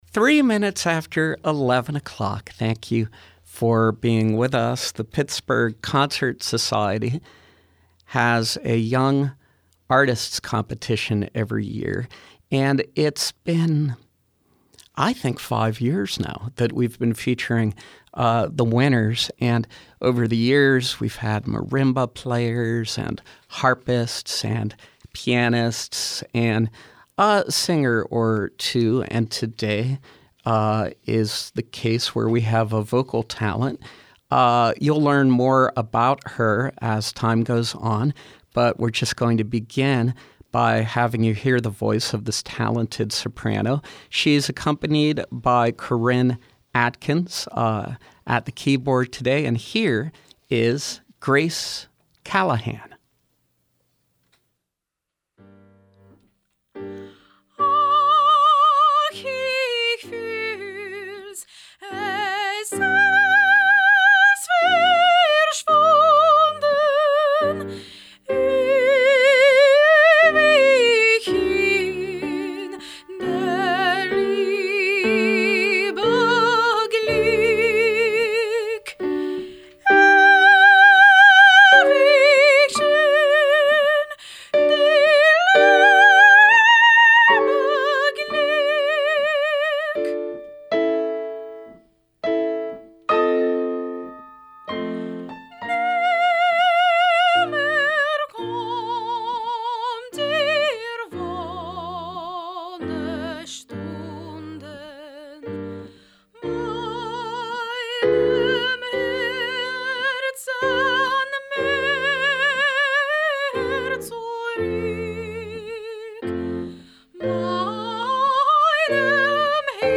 The Pittsburgh Concert Society’s Young Artists Competition provides a recital opportunity and support to talented classical musicians.
Soprano, performing selections live on SLB.